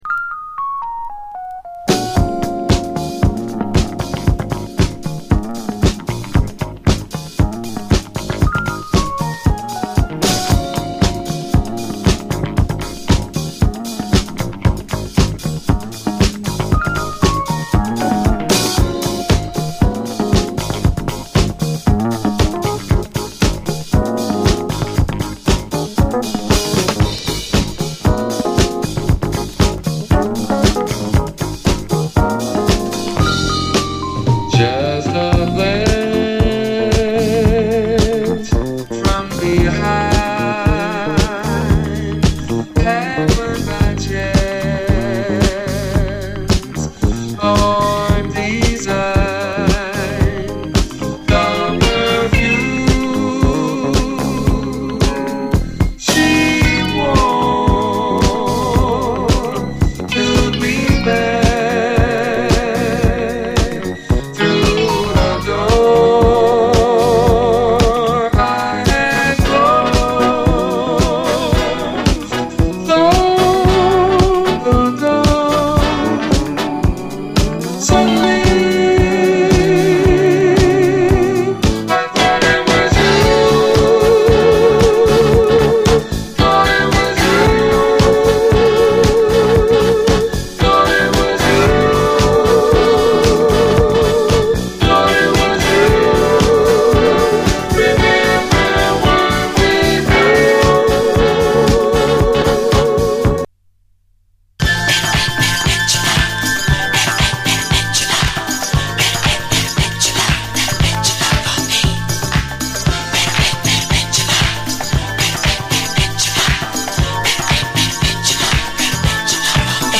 SOUL, 70's～ SOUL, DISCO
傑作ヴォコーダー・ソウル